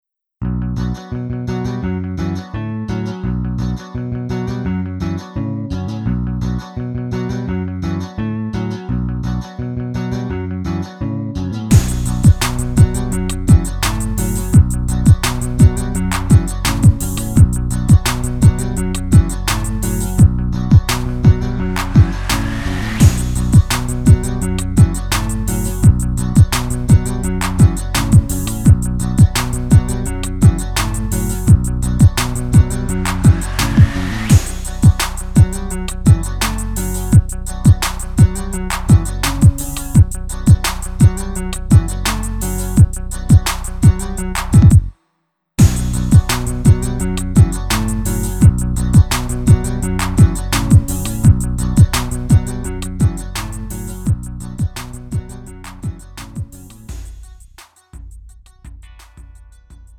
음정 -1키
장르 구분 Lite MR